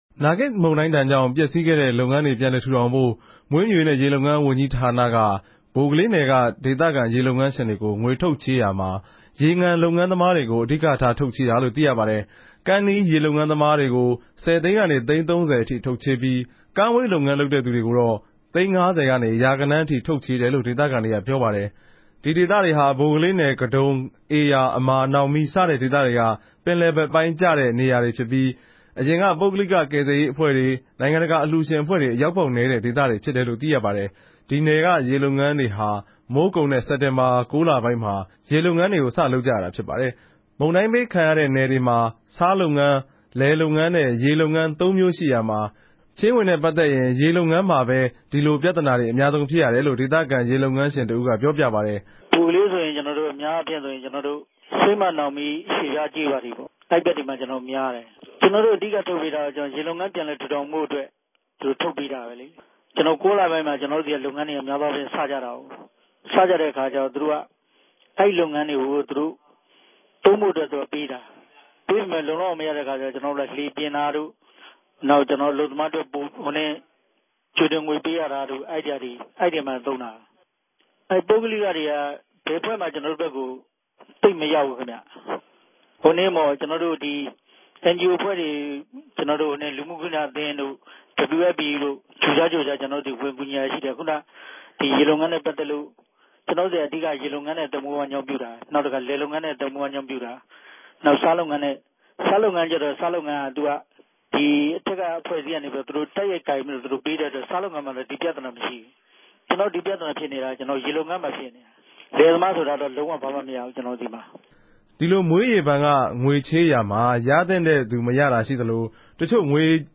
ရေလုပ်ငန်းရြင်တဦး။      "မြေး/ရေ (မြေးူမြရေးနဲႚ ရေလုပ်ငန်း) ဘဏ်ကနေ ပေးမယ်ဆိုလိုႛ ရန်ကုန် မြေး/ရေ (မြေးူမြရေးနဲႚ ရေလုပ်ငန်း) ဘဏ်ကို ရောက်လာတယ်၊ ရန်ကုန် မြေး/ရေ (မြေးူမြရေးနဲႚ ရေလုပ်ငန်း) ဘဏ်ကို ရောက်လာတော့ နောက်ထပ် လိုအပ်တဲ့ဟာတေကြို တင်ူပတဲ့အခၝကဵတော့ ဒၝတေကြို ူပန်လည် စိစစ်ပေးမယ် ဆို္ဘပီးတော့ နောက်ပိုင်းတင်တဲ့လူတေကြ ဘယ်လိုလုပ်လည်းဆိုတော့ ပြဲစားတေနြဲႛ သူတိုႛလုပ်ုကတာပေၝ့လေ၊ ငြေ ၁၀၀ မြာ ၁၀ ရာခိုငိံြန်းပေၝ့၊ ငြေ ၁၀ သိန်းကို ၁ သိန်းိံြန်းနဲႛ လုပ်ပေးမယ် ဆို္ဘပီးတော့ ၁၅ သိန်း သတ်မြတ်ထားတဲ့ ပုဂ္ဂိြလ်တေကြလည်း သိန်း ၃၀ ရလိုႛရ၊ နီးစပ်သလိုပေၝ့လေ ပြဲစားတေရြဲႚ ကဋ္ဋမ်းကဵင်မအြပေၞ မူတည်္ဘပီးတော့ ပေးုကတယ်။ ကဵနော်တိုႛဆီမြာ တကယ်တန်း ရေလုပ်ငန်းအတြက် လိုအပ်တဲ့ လူတေကြ နားမလည်တဲ့အခၝကဵတော့ ဘယ်ကိုတင်ရမယ် မသိဘူး၊ မသိတဲ့အခၝကဵတော့ တခဵိြႚမတင်ရတဲ့ သူတေလြည်း အမဵားဋ္ဌကီးပဲ။"